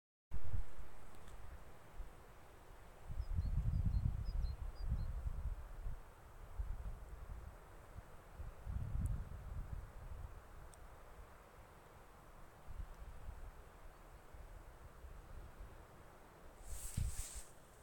Birds -> Tits ->
Great Tit, Parus major
StatusVoice, calls heard